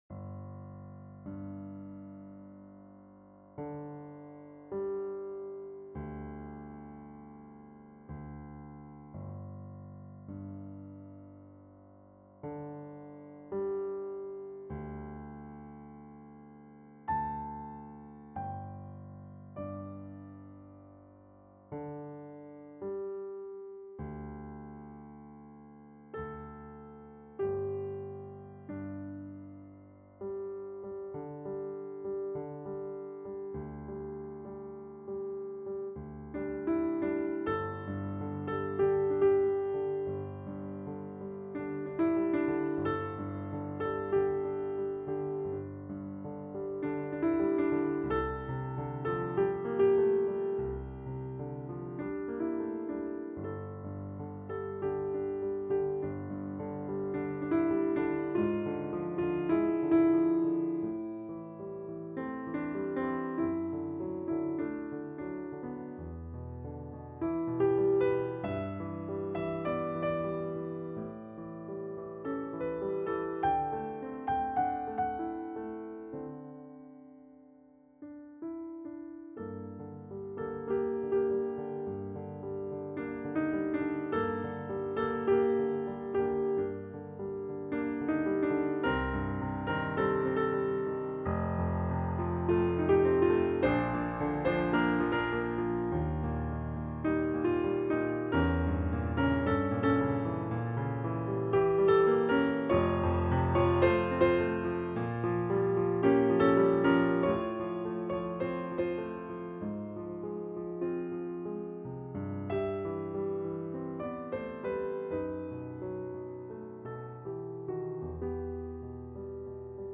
No. 33 "Voix de l'Oiseau" (Piano